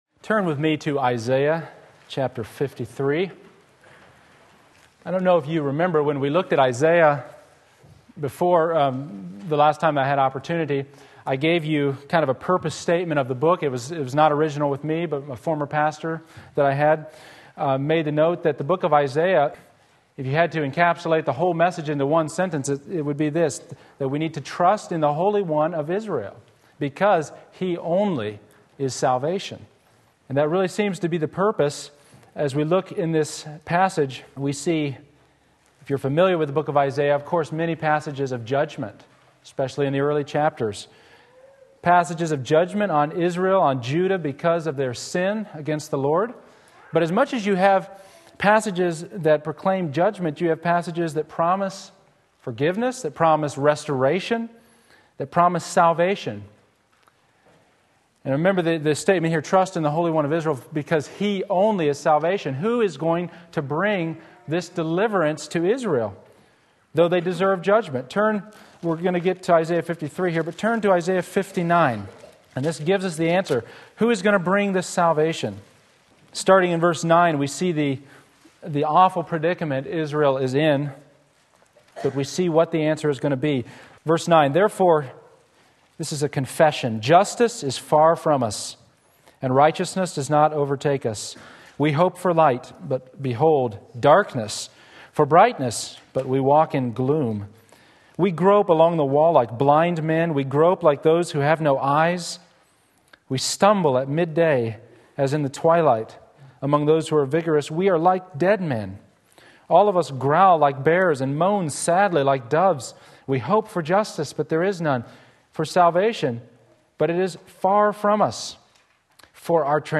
Sermon Link
The Unexpected Work of the Arm of the Lord Isaiah 53:1-6 Sunday Morning Service